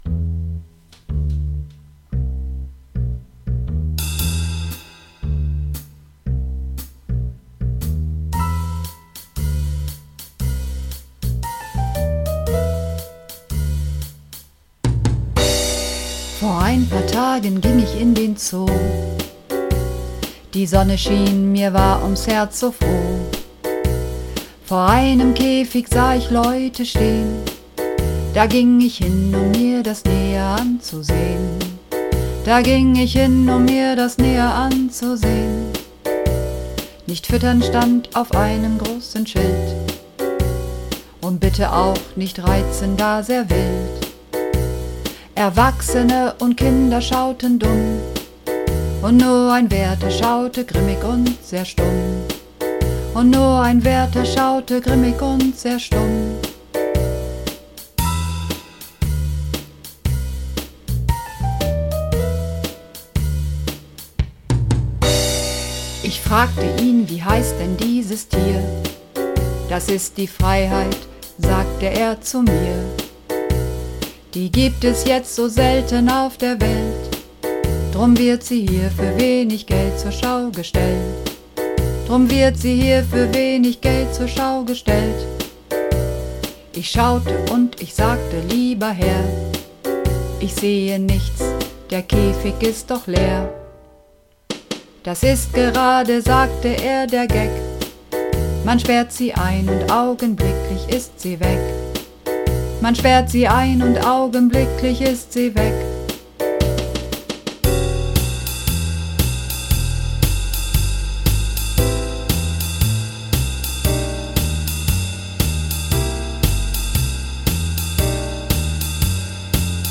Runterladen (Mit rechter Maustaste anklicken, Menübefehl auswählen)   Die Freiheit (Bass)
Die_Freiheit__2_Bass.mp3